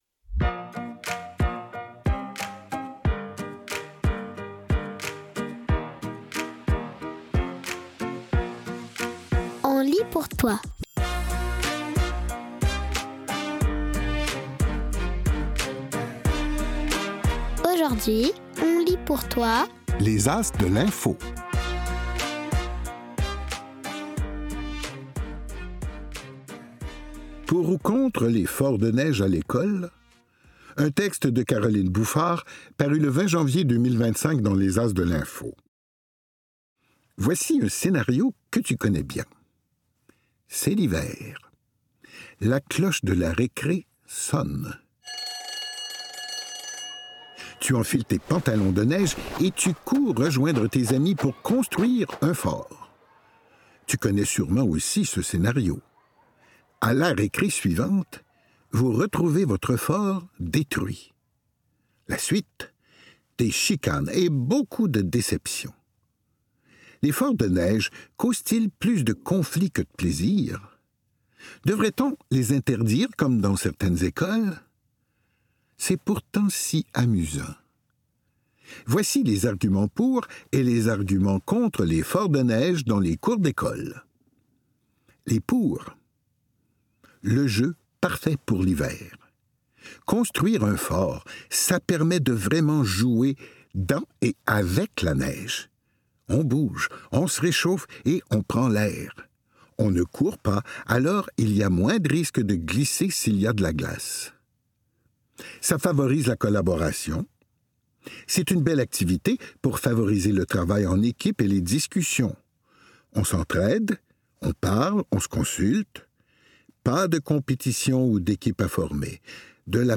Images et mots, à voix haute! POUR OU CONTRE les forts de neige à l’école?